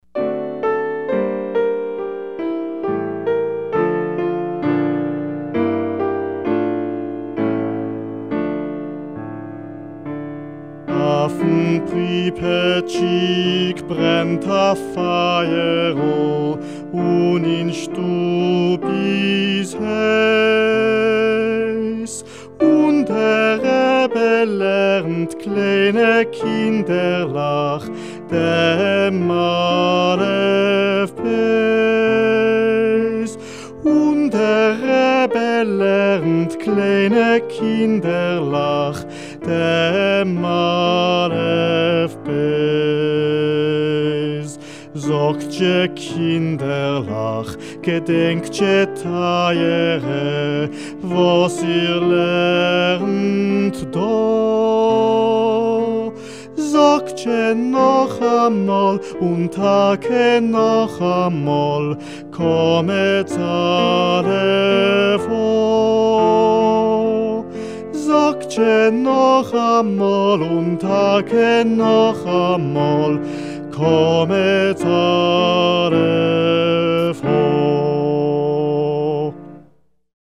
Varshavski-Afn-Pripetshik-gezungen.mp3